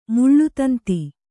♪ muḷḷu tanti